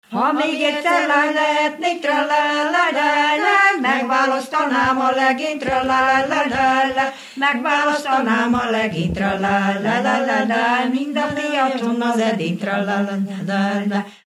Erdély - Nagy-Küküllő vm. - Nagymoha
Műfaj: Szökő
Stílus: 4. Sirató stílusú dallamok
Kadencia: 5 (4) 5 1